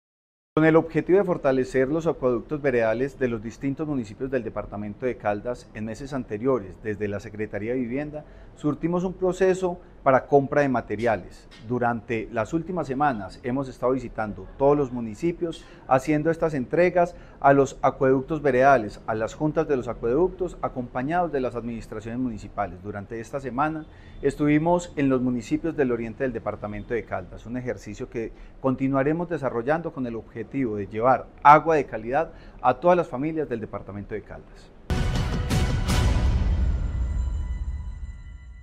Secretario de Vivienda de Caldas, Francisco Javier Vélez Quiroga.